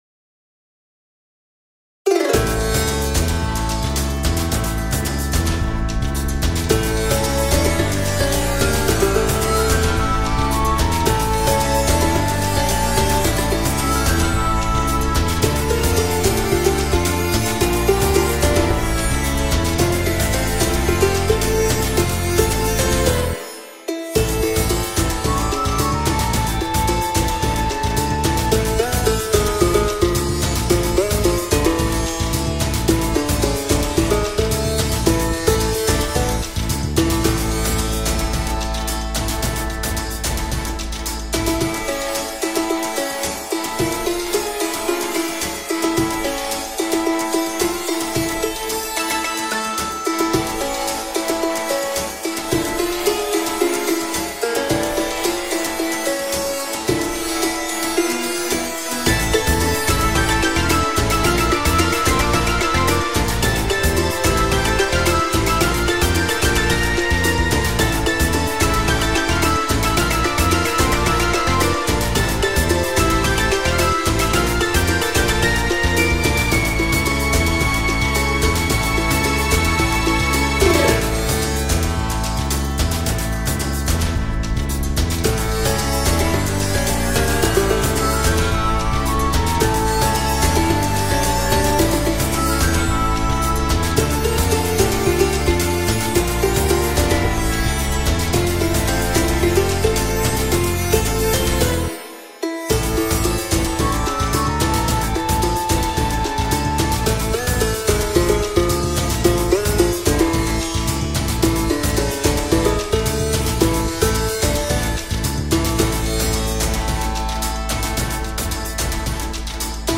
genre:soundtrack